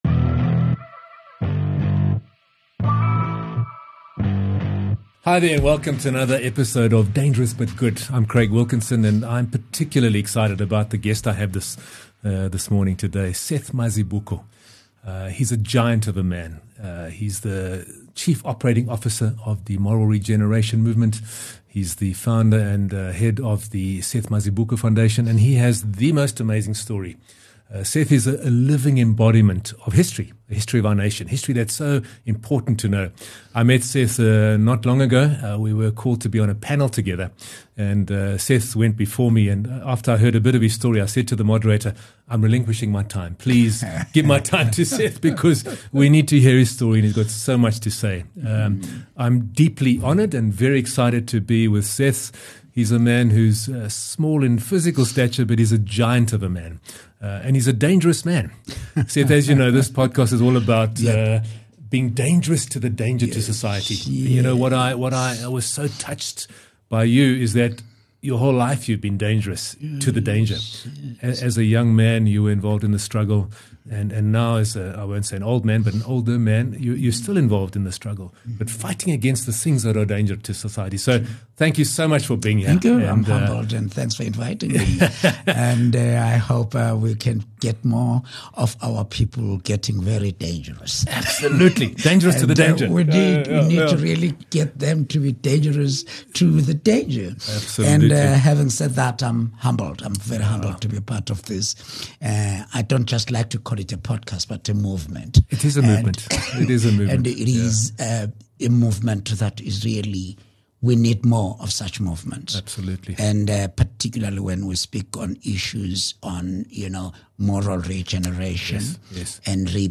profound conversation about courage, sacrifice and the fight for true freedom. Learn how to be an activist for love and truth and a danger to corruption and oppression in all its forms.